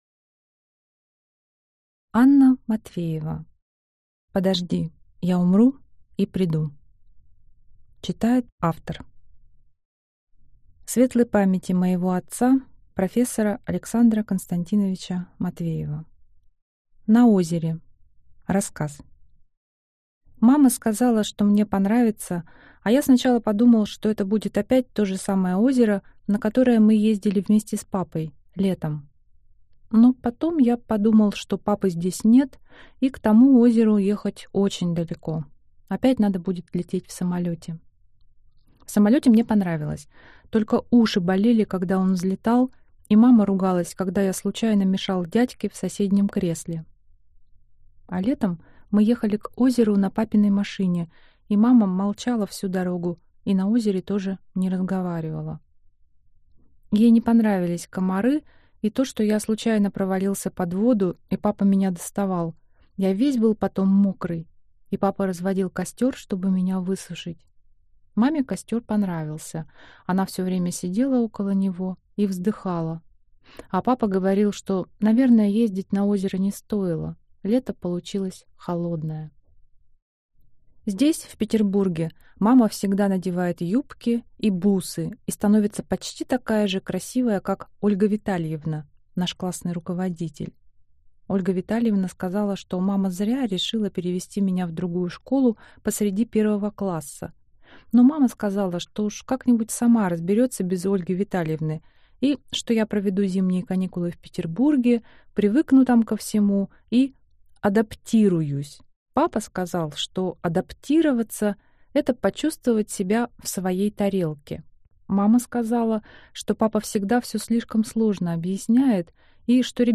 Аудиокнига Подожди, я умру – и приду (сборник) | Библиотека аудиокниг